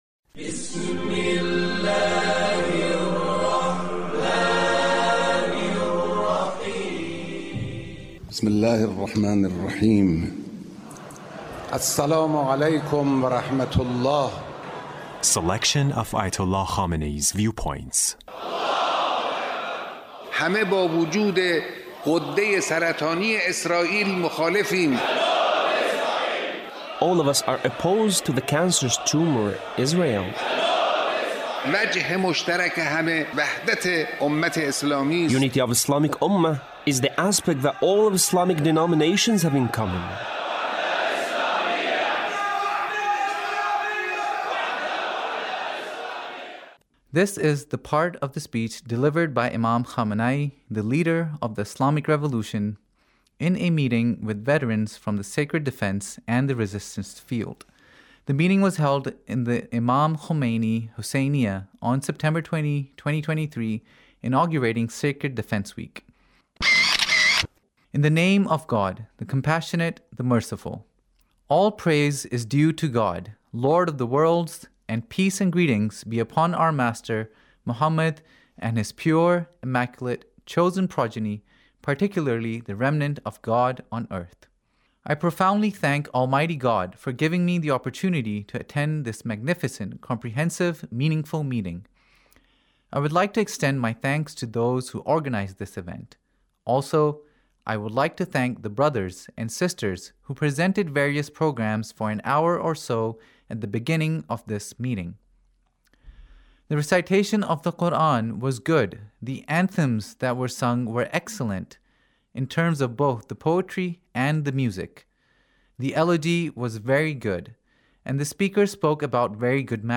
Leader's Speech (1870)
Leader's Speech about Sacred defense